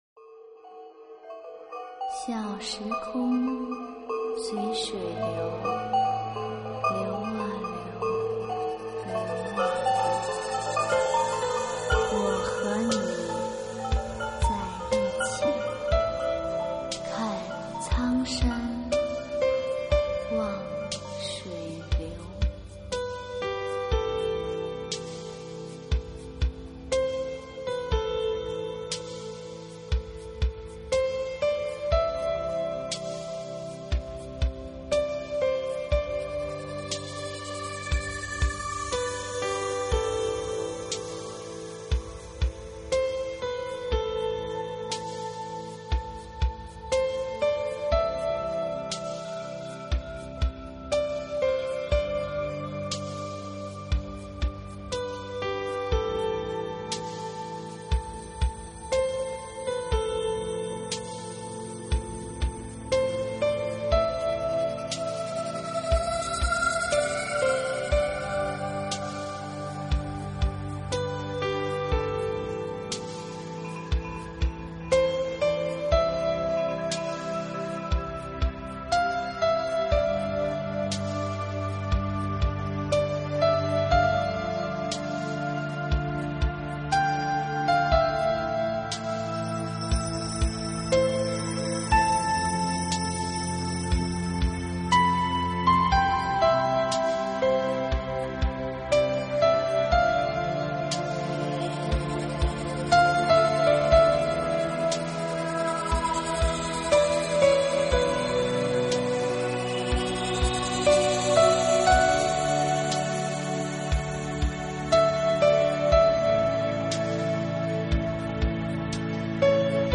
专辑平和宁静，舒缓心情的极佳选择。